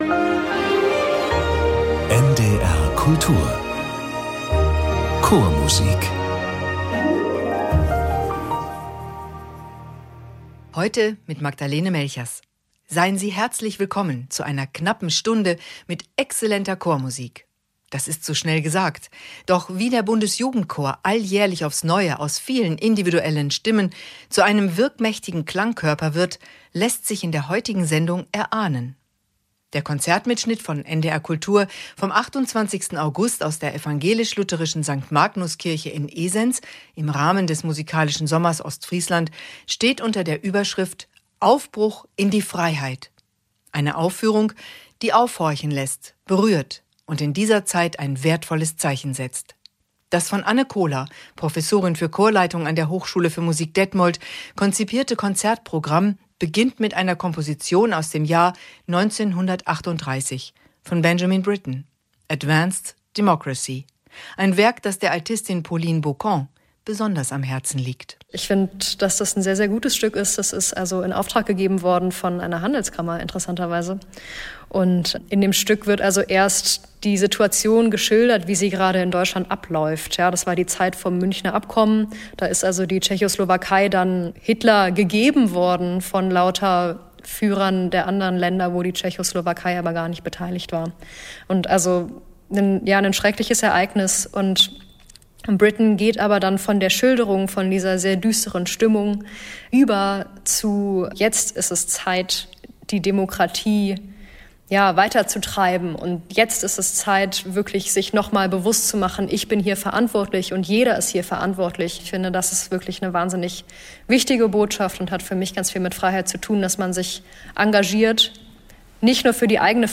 Dieser Klangkörper bietet nicht nur exzellente Vokalkunst, sondern gleichermaßen inhaltlich anspruchsvolle Programme.